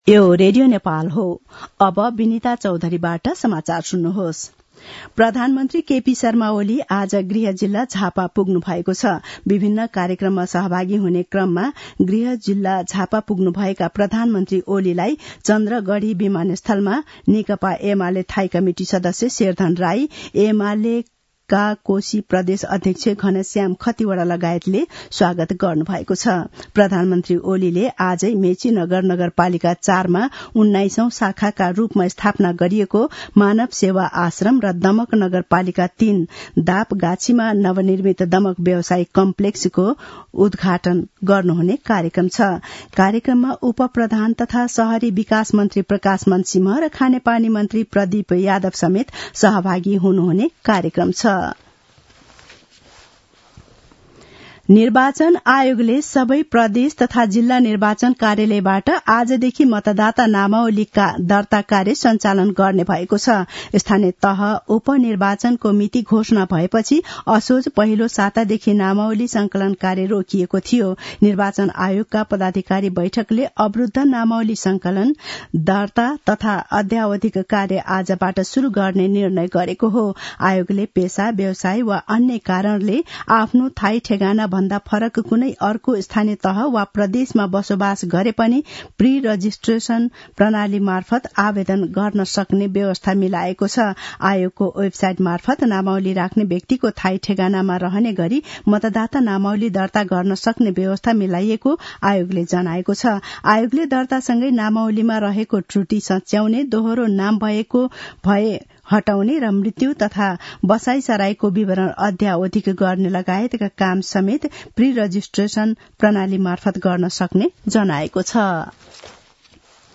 दिउँसो १ बजेको नेपाली समाचार : २४ मंसिर , २०८१
1-pm-nepali-news-1-6.mp3